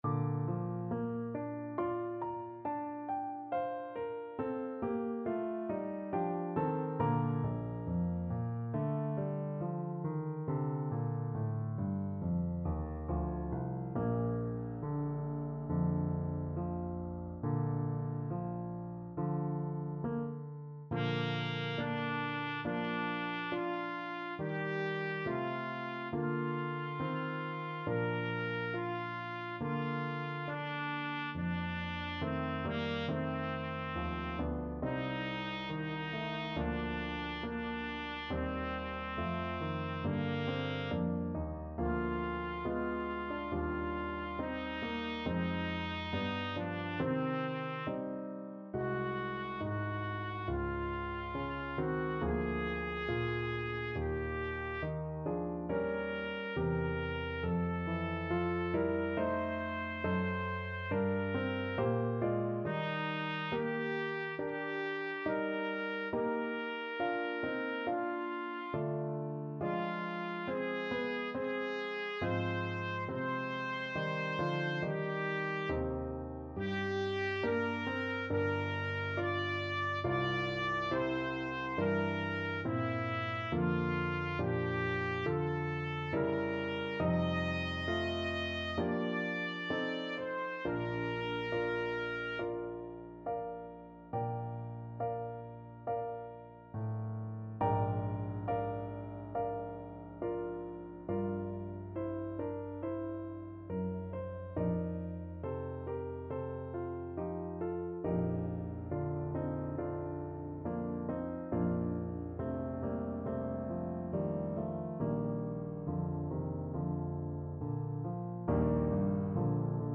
Trumpet version
2/2 (View more 2/2 Music)
Slow =c.69
Classical (View more Classical Trumpet Music)